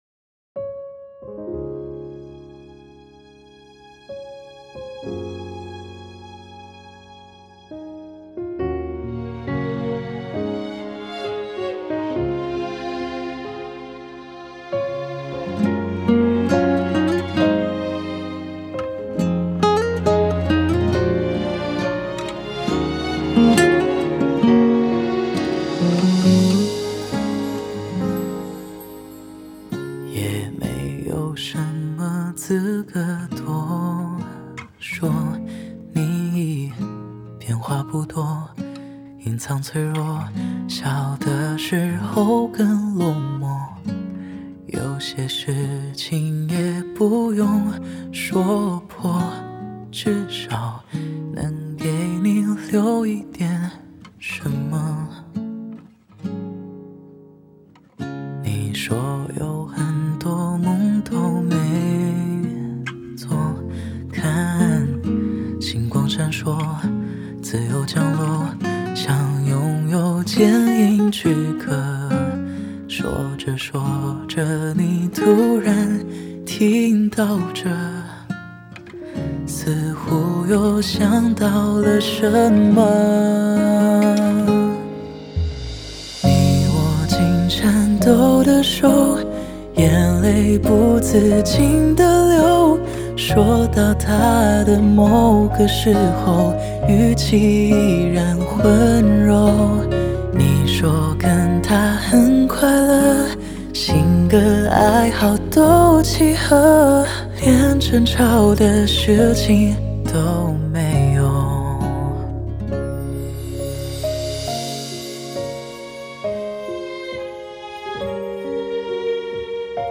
Ps：在线试听为压缩音质节选
吉他